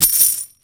cash.wav